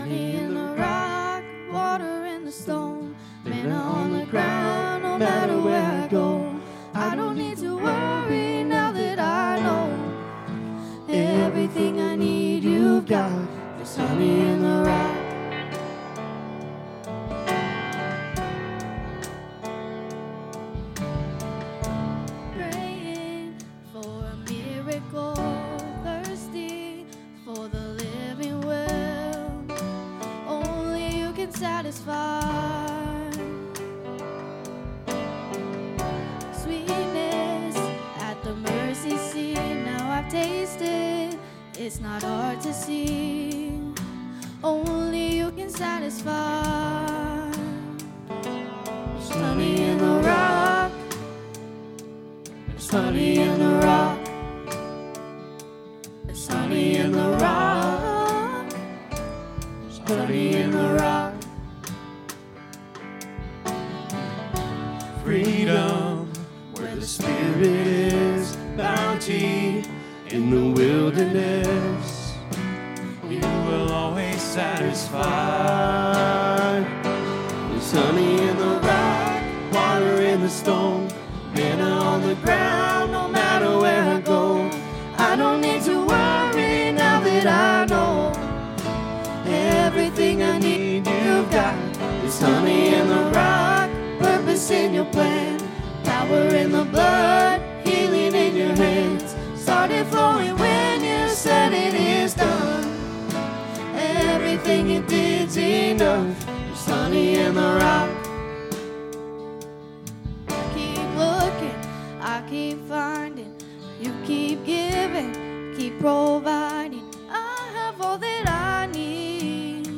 FBC Potosi - Sunday Service